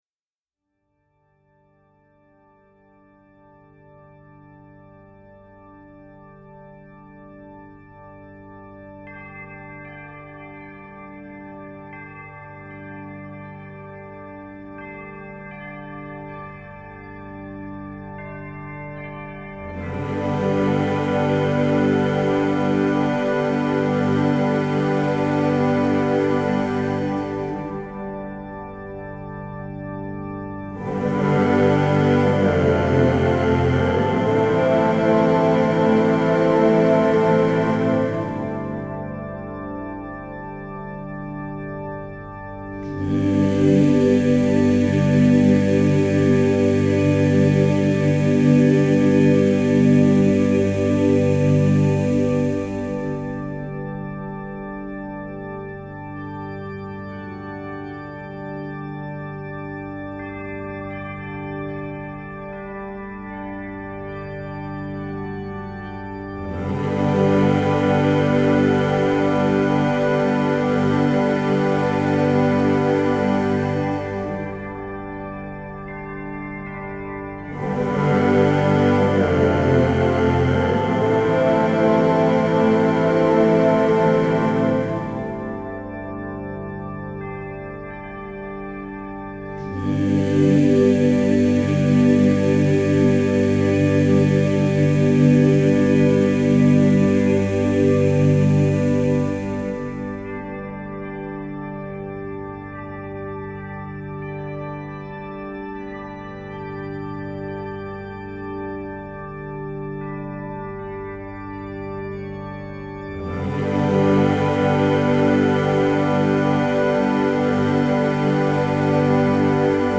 CHANTS REIKI